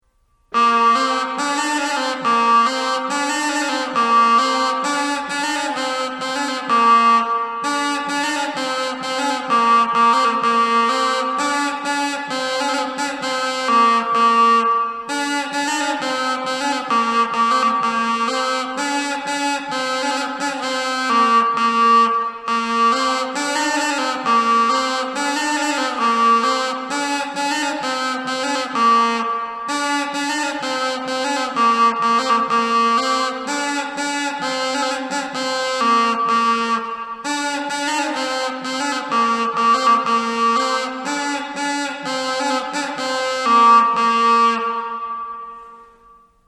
wooden birbyne